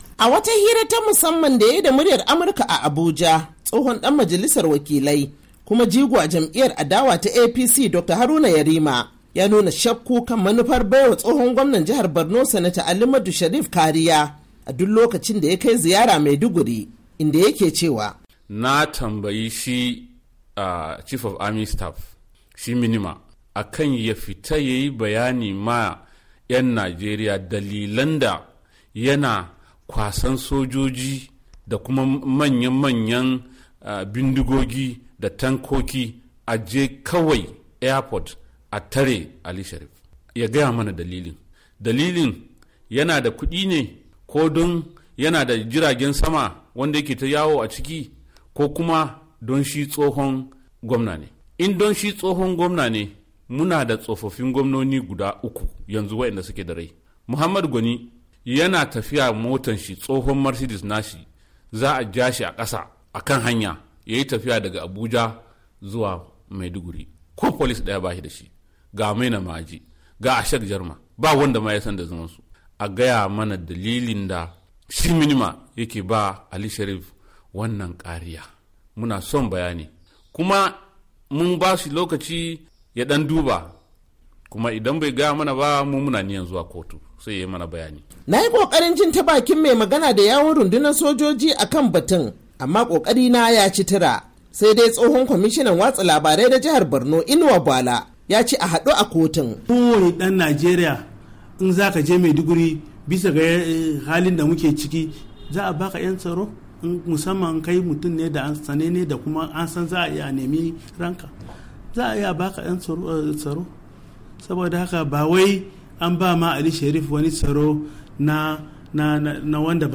A cikin firar da yayi da Muryar Amurka tsohon dan majalisar wakilai kuma jigo a jam'iyyar APC Dr. Haruna Yerima ya nuna shakku kan baiwa tsohon gwamnan Borno Ali Modu Sheriff Kariya.